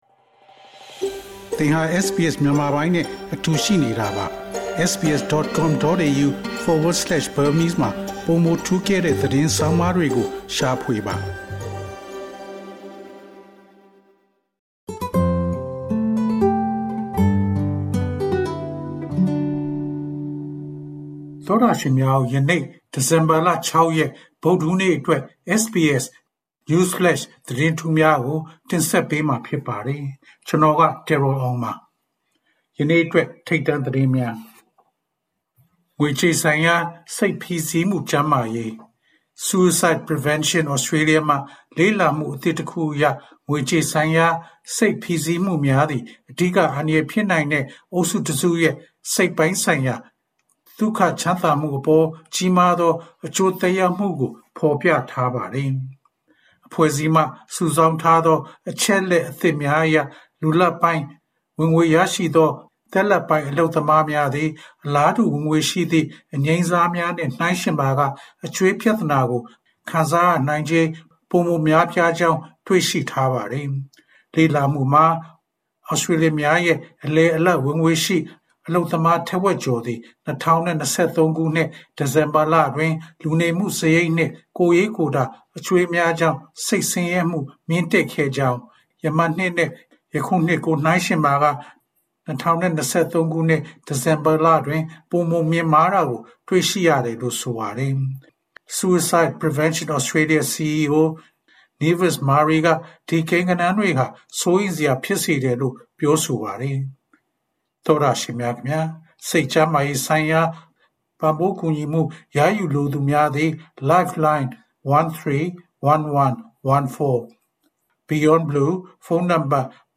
SBS မြန်မာပိုင်း အစီအစဉ်ပေါ့ကတ်စ်သတင်း။